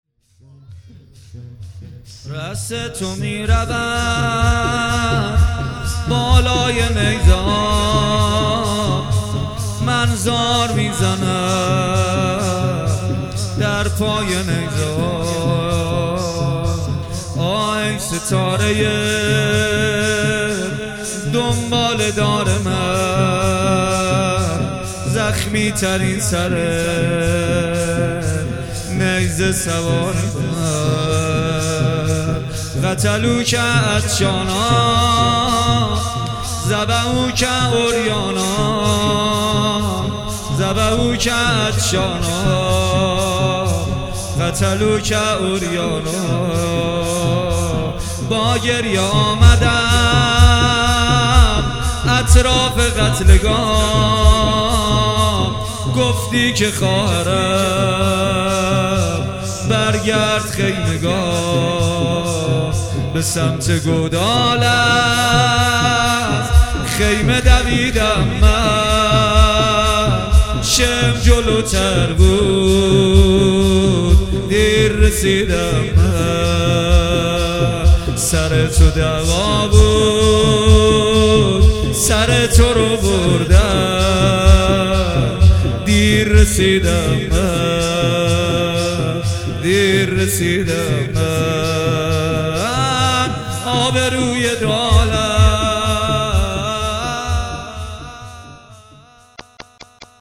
آخرین خبر | مداحی شب اول محرم 1399 با نوای کربلایی محمد حسین پویان‌فر